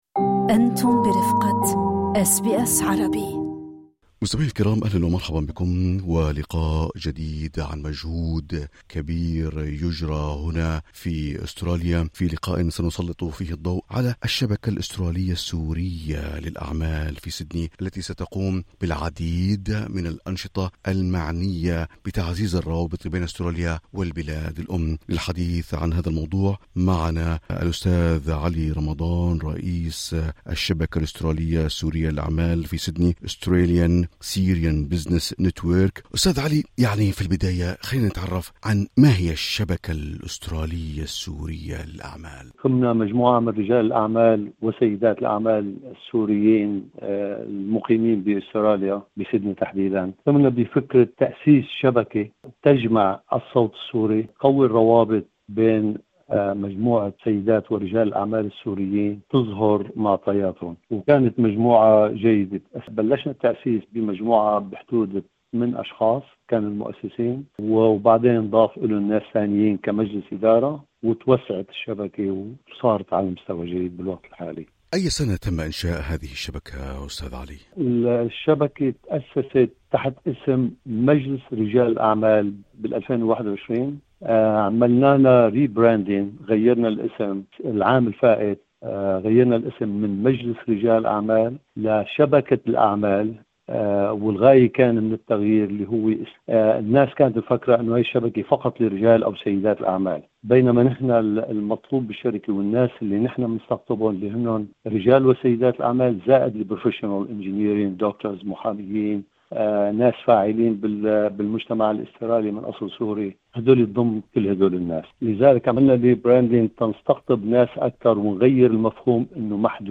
للاستماع إلى أحدث التقارير الصوتية والبودكاست، اضغطوا على الرابط التالي استمعوا لتفاصيل هذا اللقاء، بالضغط على زر الصوت في الأعلى.